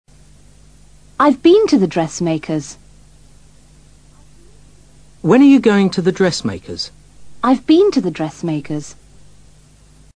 Cuando deseamos enfatizar una palabra en una oración, decimos esa palabra en voz más elevada que lo normal (more loudly) y también lo hacemos utilizando un tono más alto (a higher pitch).